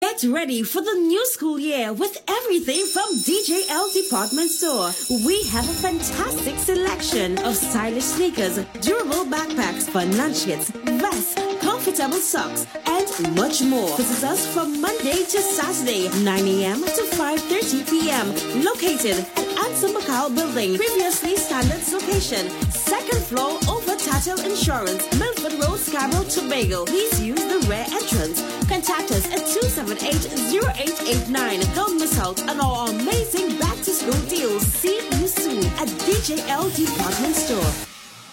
Top 5 Radio Ads